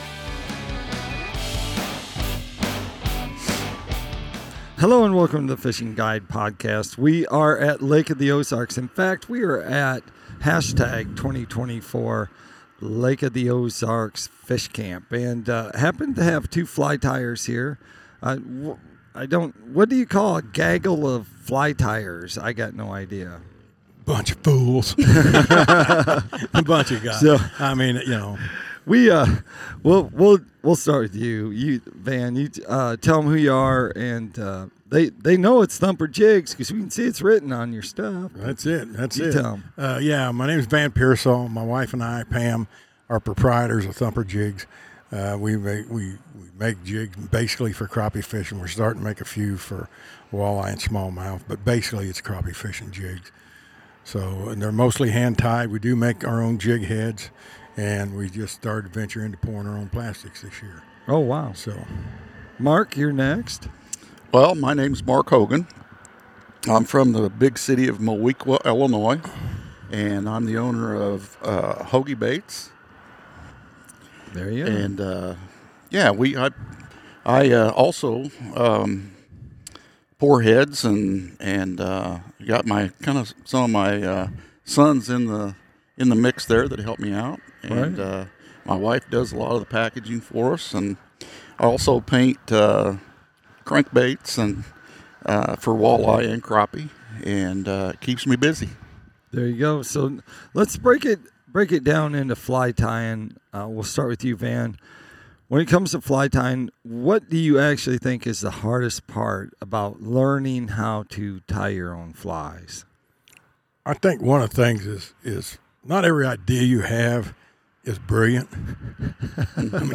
interviews custom fly tiers